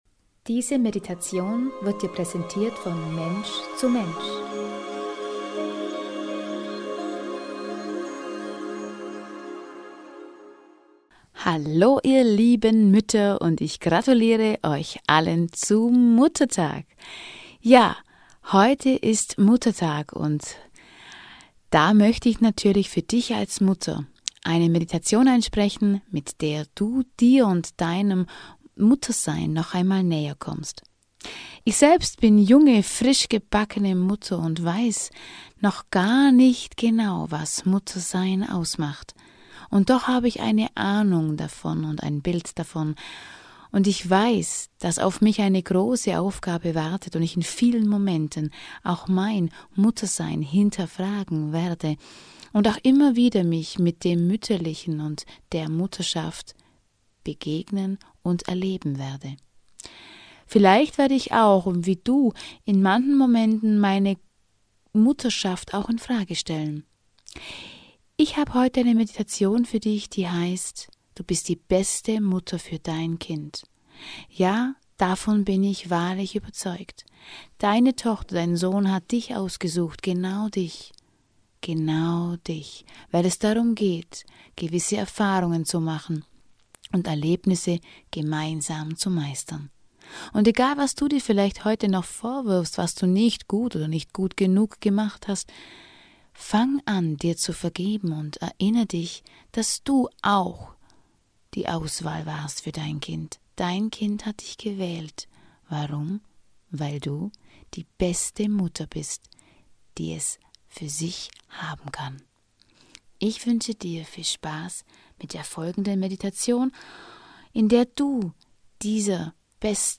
In dieser Meditation begegnest du der besten Version deiner Selbst und kannst danach gelassen und liebvoll deine Mutterschaft leben und lieben.